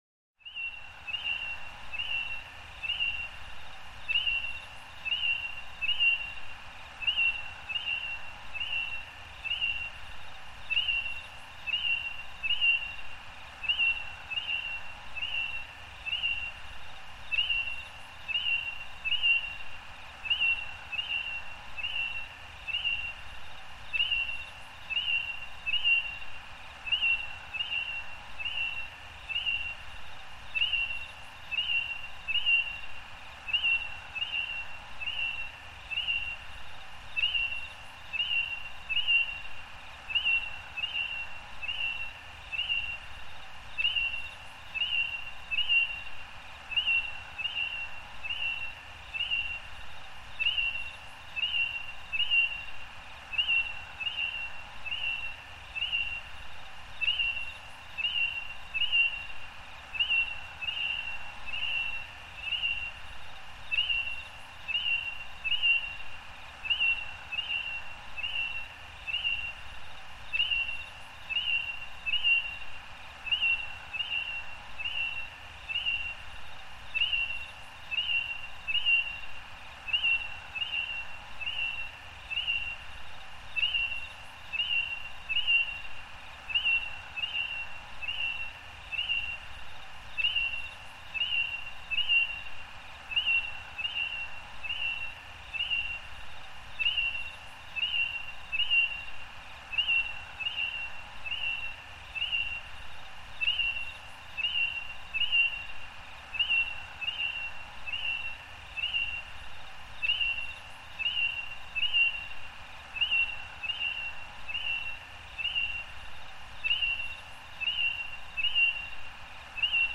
دانلود صدای پرنده 9 از ساعد نیوز با لینک مستقیم و کیفیت بالا
جلوه های صوتی